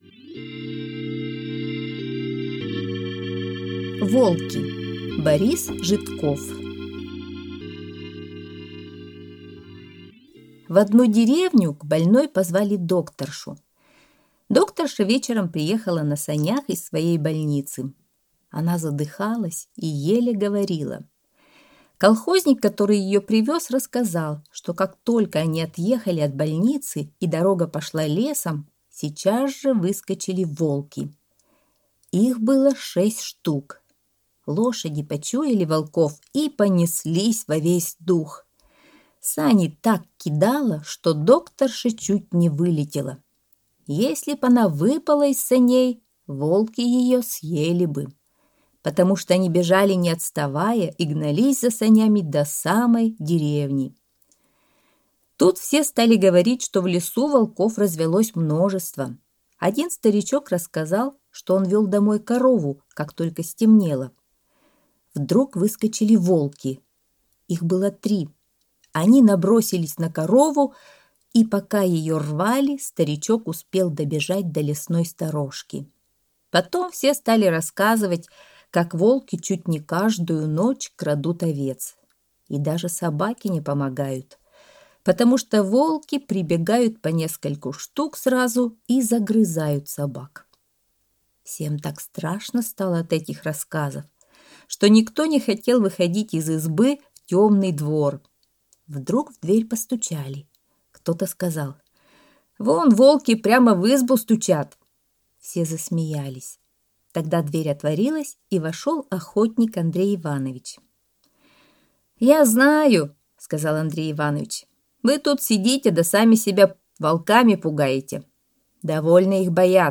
Аудиорассказ «Волки»